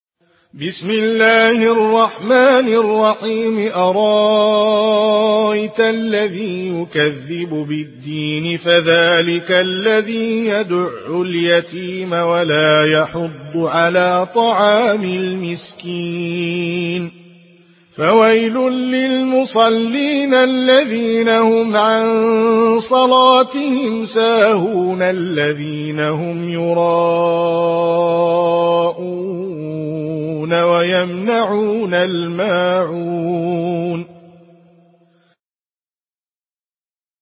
in Warsh Narration
Murattal Warsh An Nafi